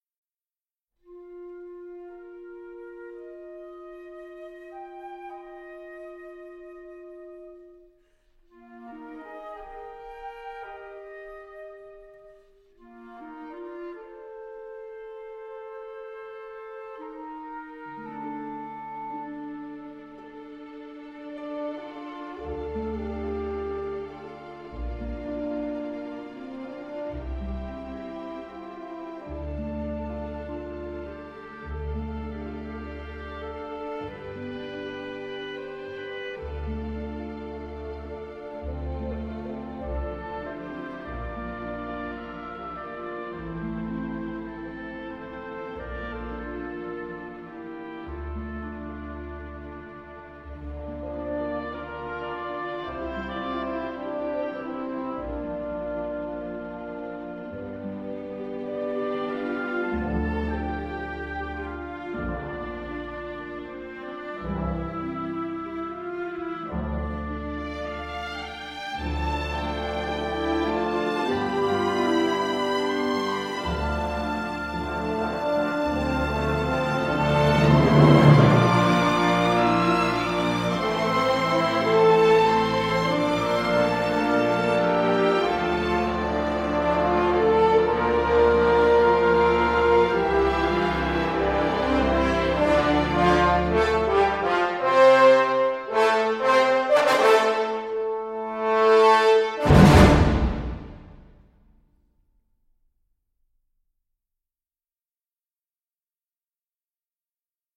Réenregistrement impeccable